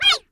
17 RSS-VOX.wav